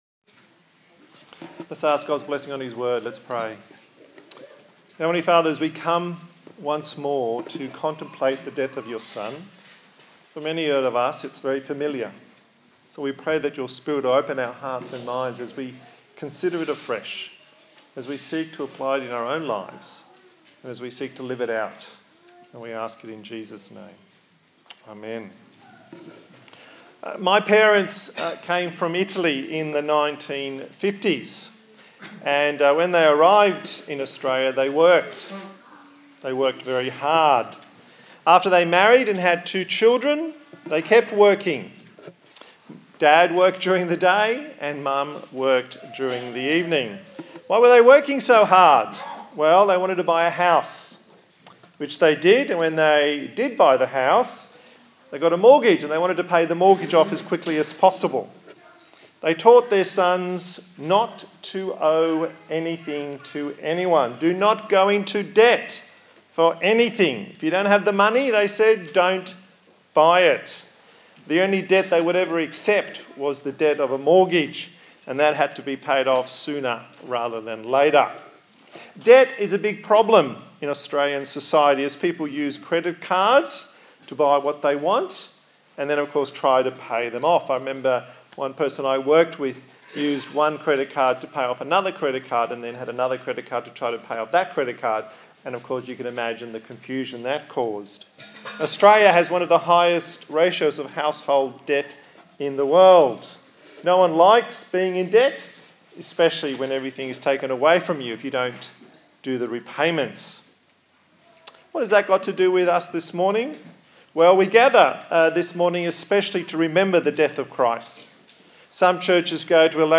Good Friday Passage: Matthew 26 & 27 Service Type: Good Friday